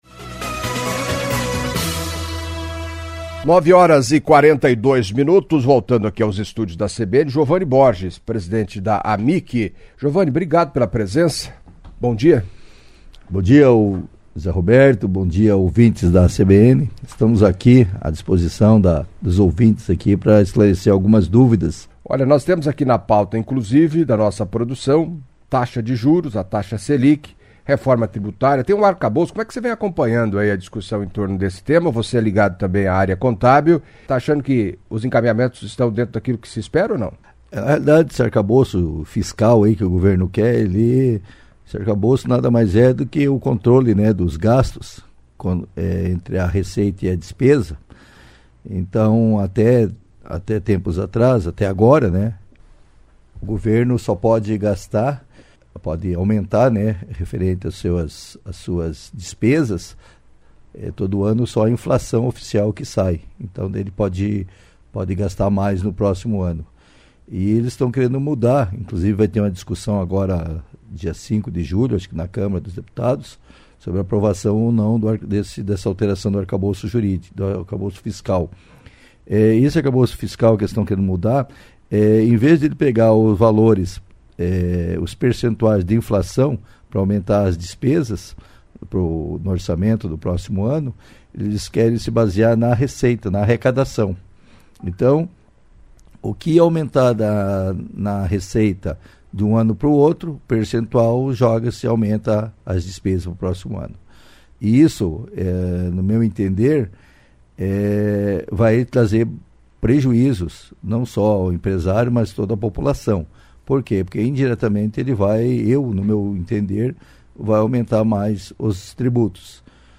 Em entrevista à CBN Cascavel nesta sexta-feira (23)